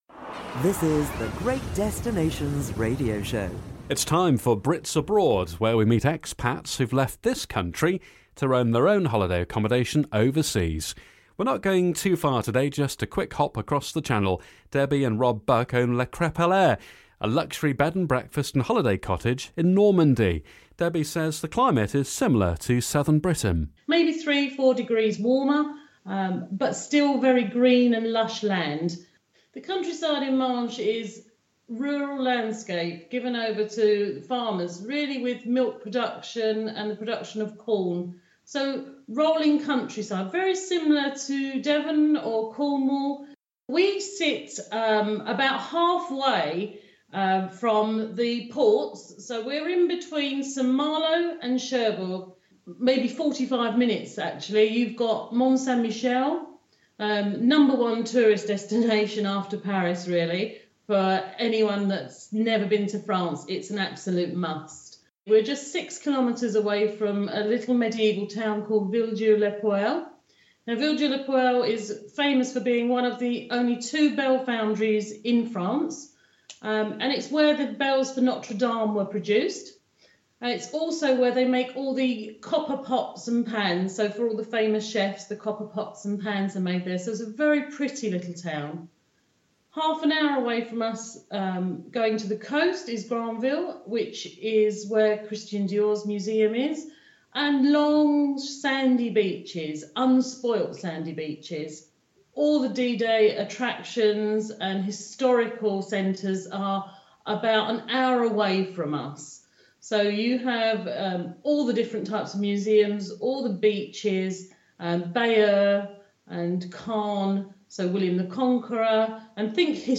talks with the couple.